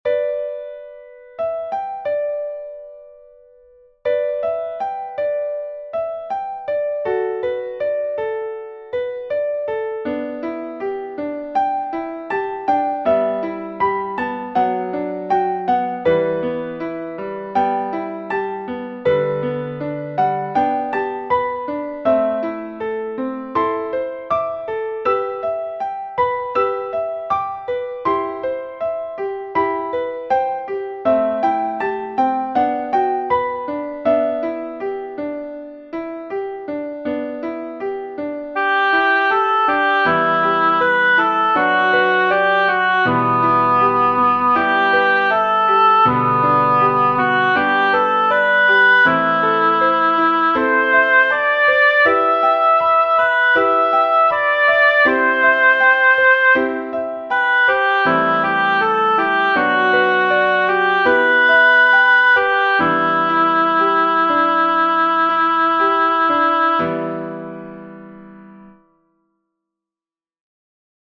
「年，又過年」，為這詩譜曲，和弦用了香港人耳熟能詳的《新春頌獻》的首句引入，但原詩跟所譜的曲都是悽然的；或許只可「共」慶歡樂年年；獨者，則苦不成眠……
除夜作pno.mp3